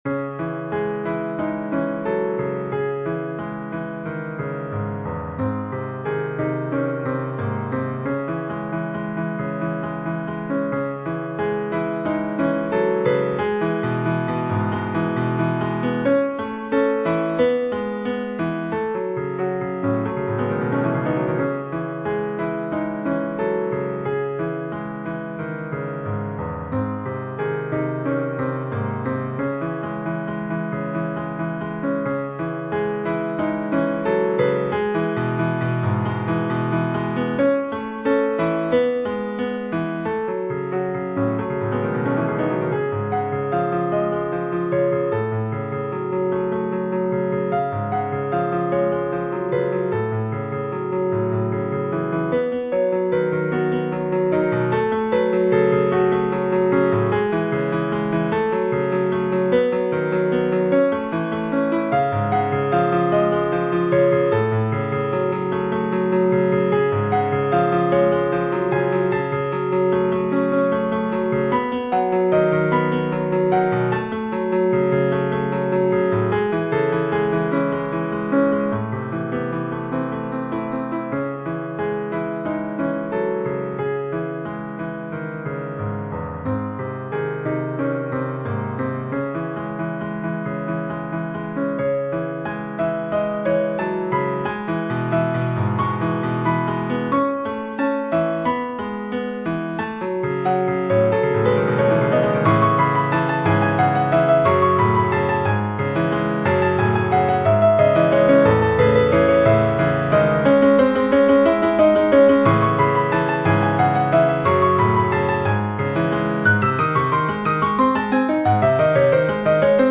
Fantasy (piano piece)